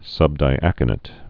(sŭbdī-ăkə-nĭt)